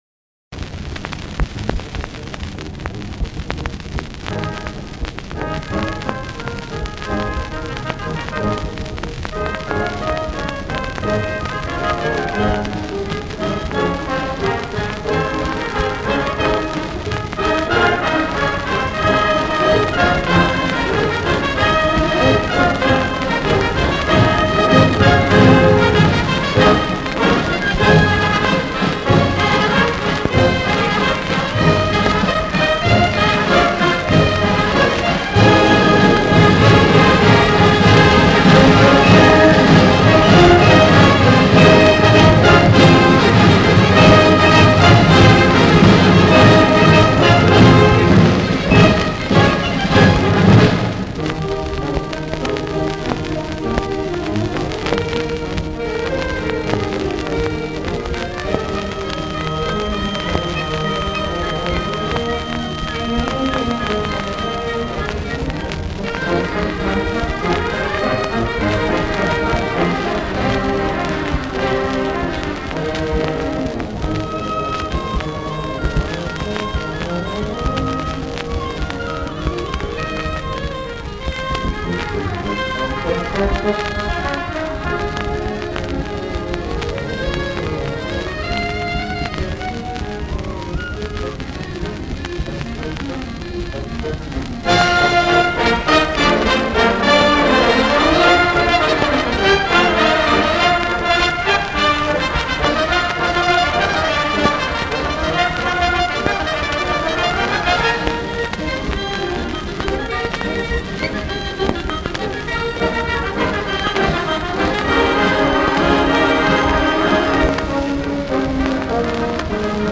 This gallery contains audio highlights from the St. Philip Basilica High School Concert Band Annual Orchestra Hall concert of 1962.
St. Philip Basilica High School Concert Band
Orchestra Hall, Chicago, Illinois 1962